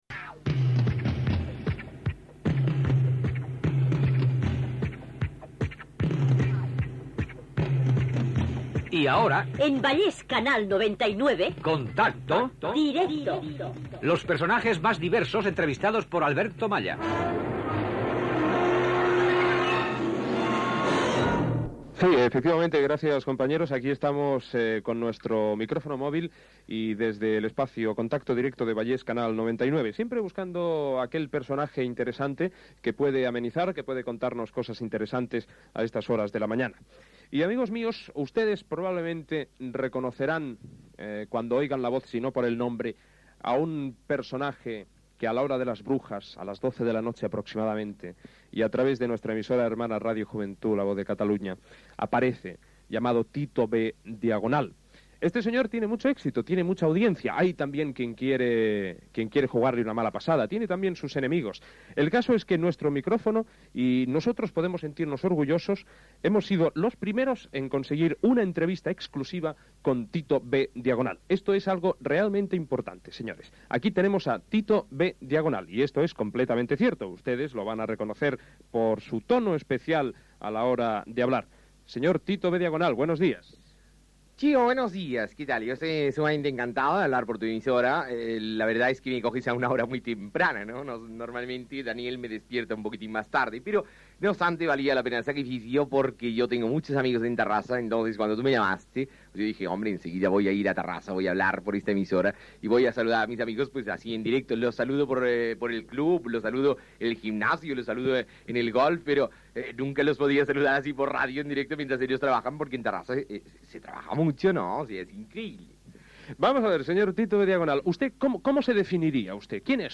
Secció "Contacto directo" amb la primera entrevista feta al personatge Tito B. Diagonal de radio Juventud (interpretat per Jordi Estadella).
Entreteniment